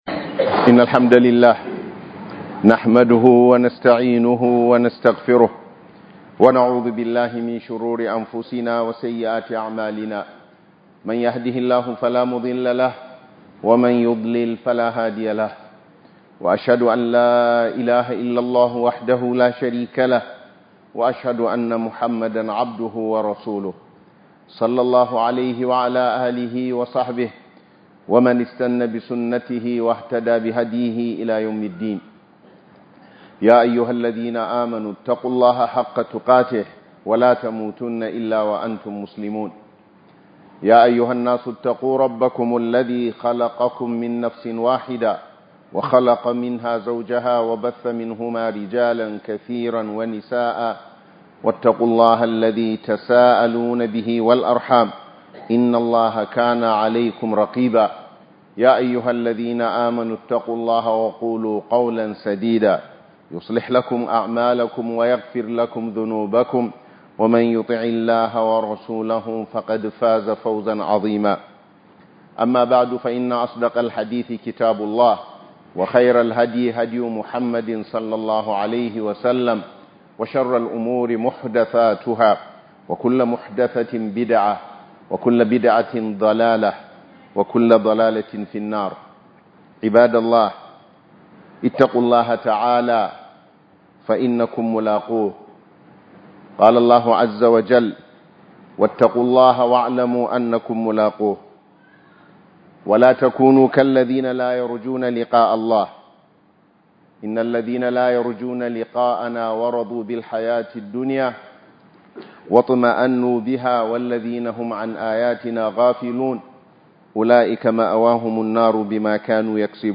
Annasihat - Yin Nasiha - HUDUBA